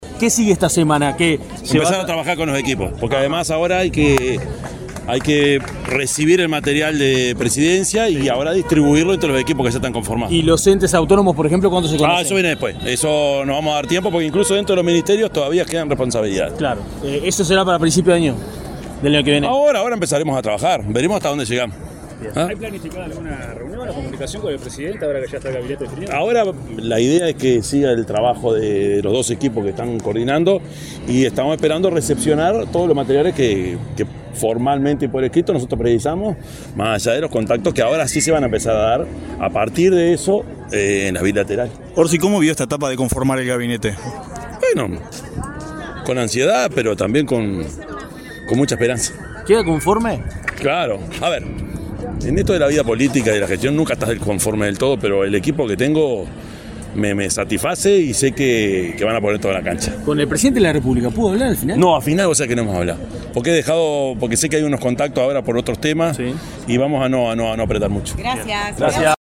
Orsi encabezó una conferencia de prensa en el mediodía de este lunes para anunciar su gabinete.